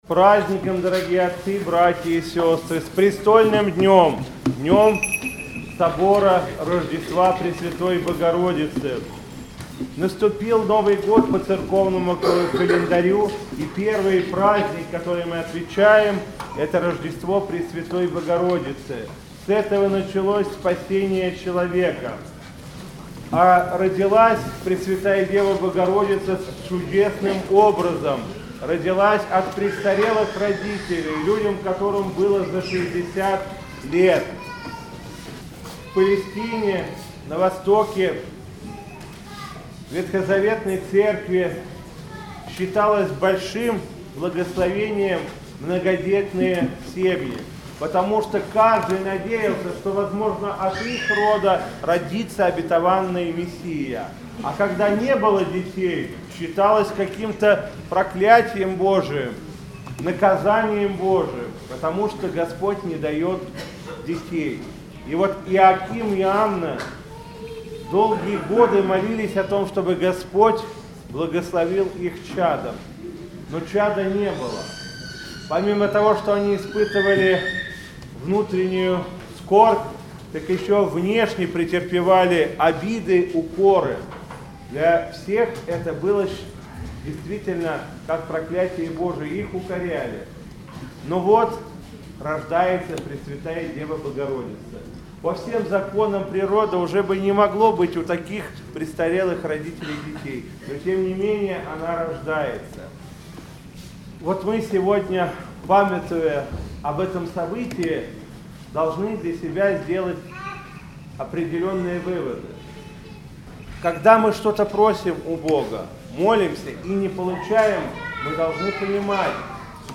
Владыка Игнатий поздравил прихожан с престольным праздником и обратился к ним с проповедью.